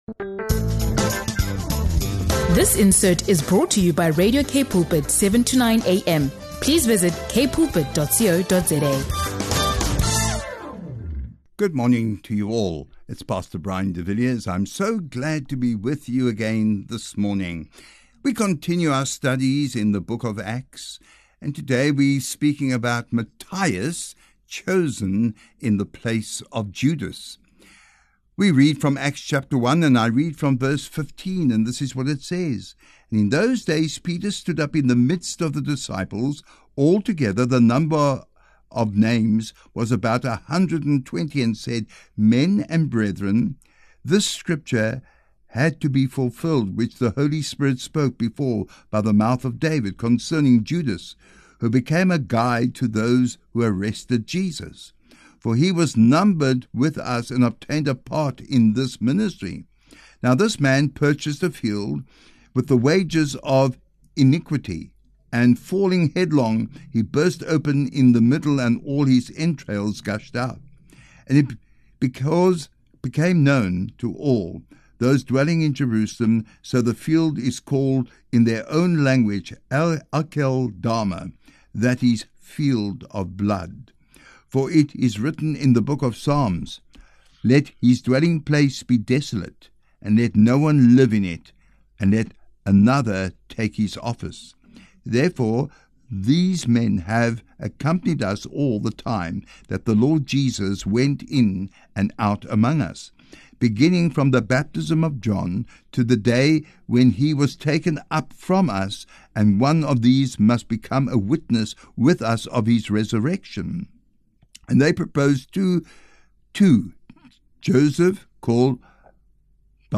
If you’re seeking biblical wisdom for leadership, ministry, church administration, or personal decision-making, this teaching will encourage you to put prayer first and trust God’s direction.